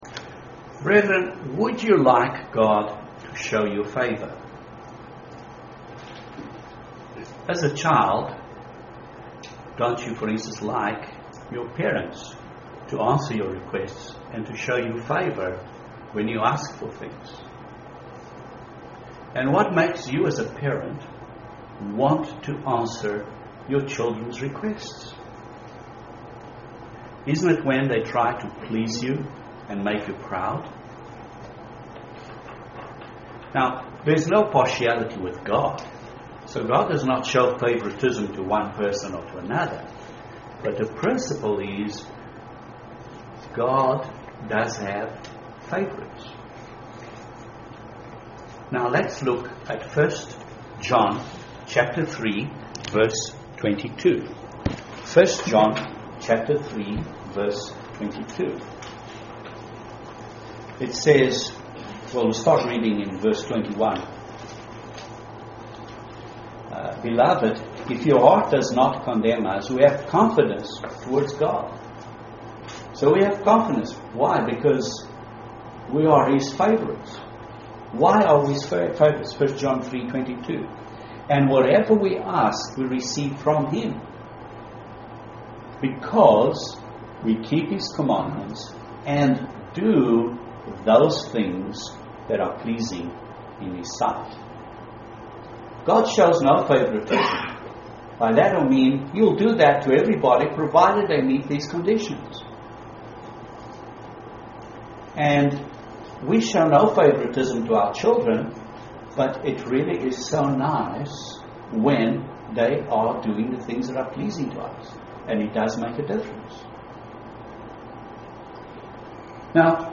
Would you like God to show you favor? Particularly favor in judgment? This sermon addresses a number of Christ's teaching's regarding showing mercy.